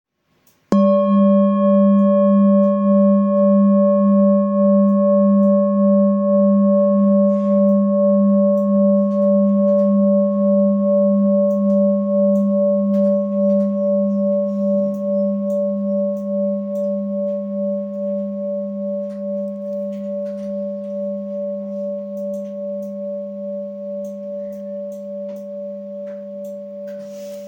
Handmade Singing Bowls-30416
Singing Bowl, Buddhist Hand Beaten, Plain, Antique Finishing, Select Accessories
Material Seven Bronze Metal